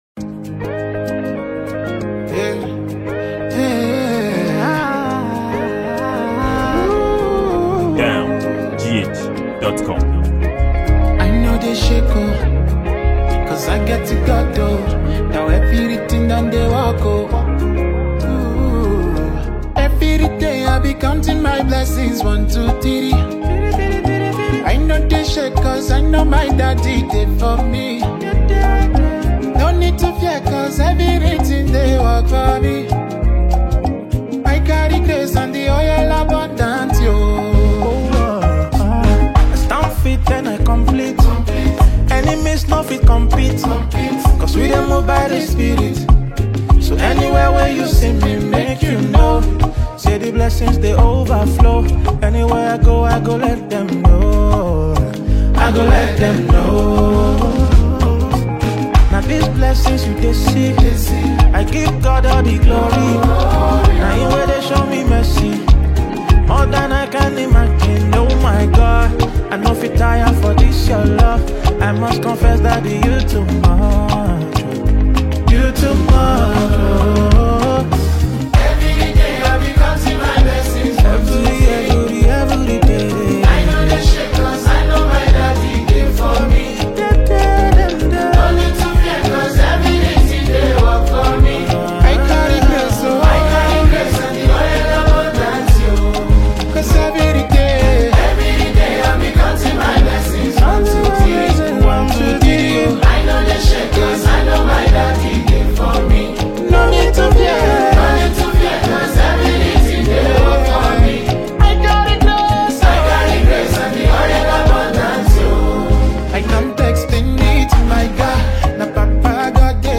Nigerian gospel singer